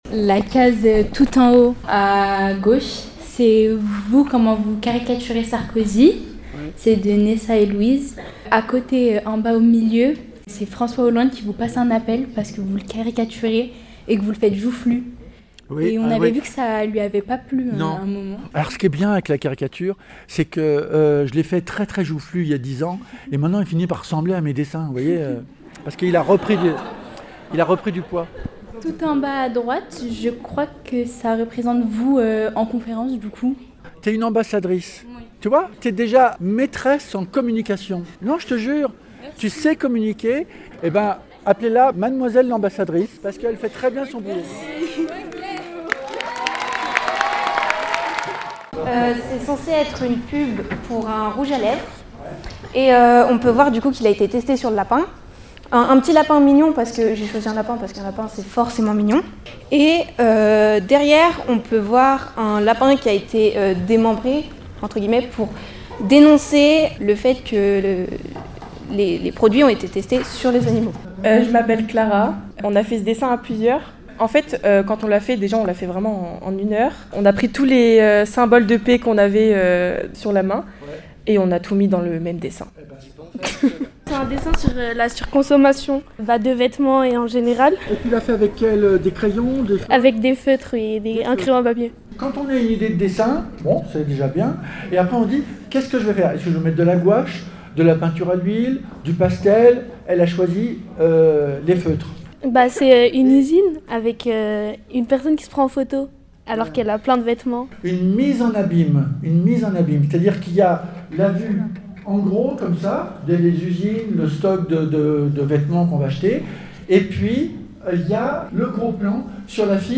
Ces échanges ont été l’occasion de discuter du rôle du dessin dans le débat d’idées, de l’engagement citoyen et de l’importance de la liberté d’expression. Voici un extrait des discussions survenues entre les jeunes et le dessinateur.
ITC LIVE-Conférence Plantu Bonneville pour la Paix